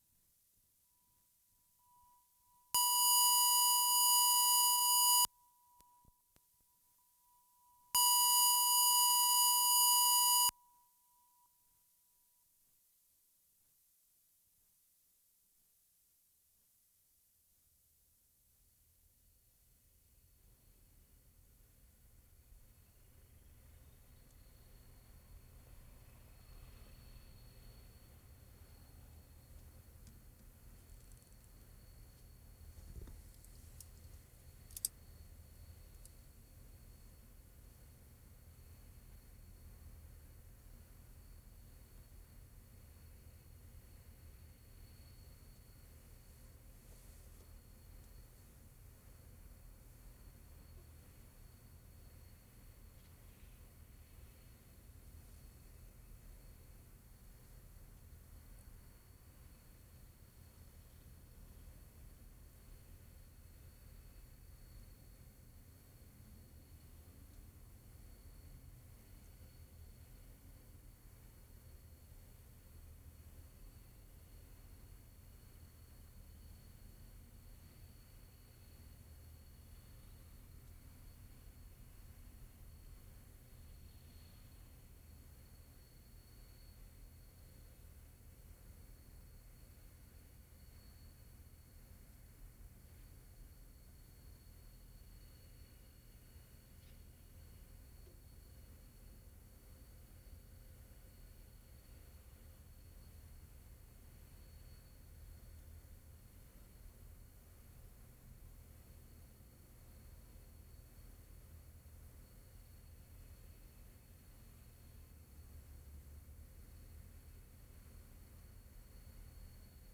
PORT COQUITLAM / PITT MEADOWS March 22, 1973
CP RAILYARDS 3'15"
1. Idling diesel engines, distant with quiet high whine. Quite nice.